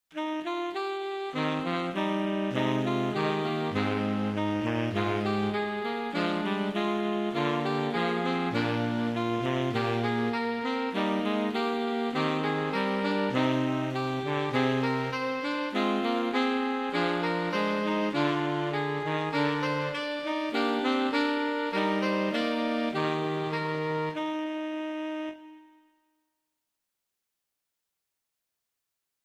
Polyfone canon-inzingoefening
In deze inzingoefening zingen alle stemmen dezelfde noten in canon. De melodie is makkelijk te onthouden, omdat er vijf keer dezelfde frase wordt gezongen, telkens een toon hoger.
Hieronder staat bijvoorbeeld de canon met twee vrouwenstemmen en één mannenstem:
inzingoefening-met-klassiekige-lijnen.mp3